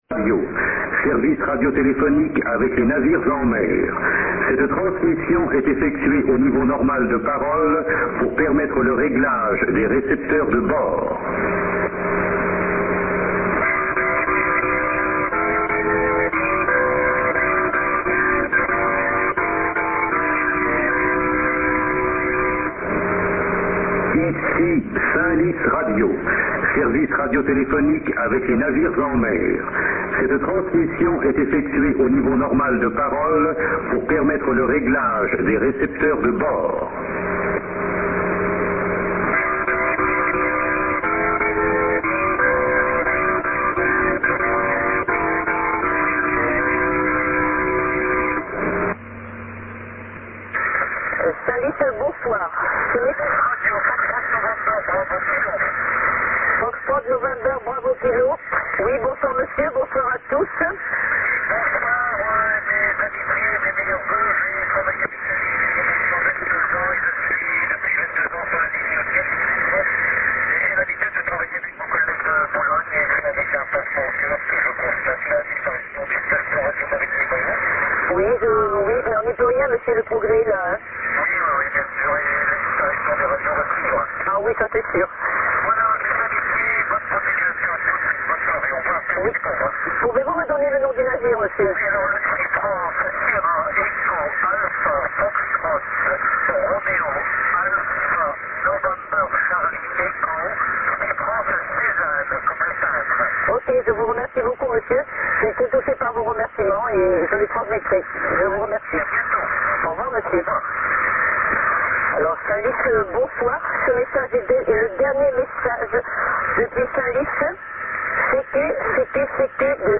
Ultima chiamata di Saint-Lys Radio.